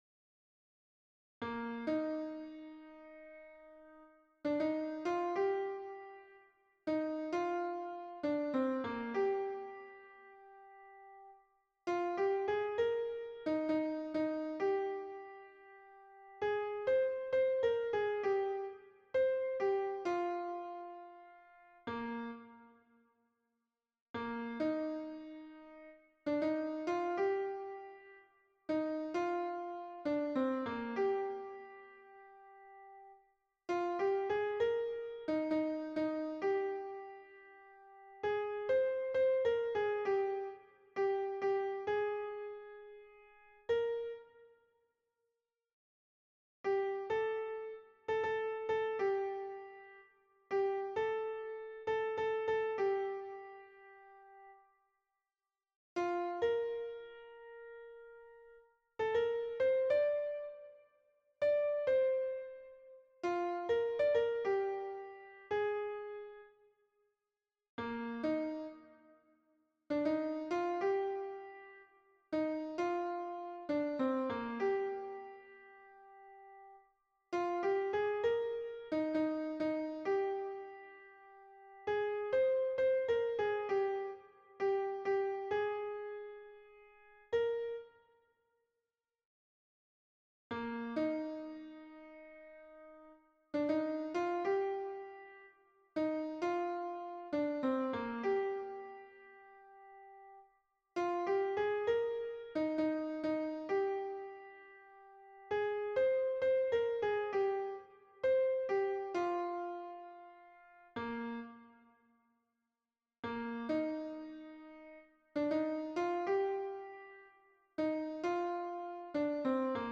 Hommes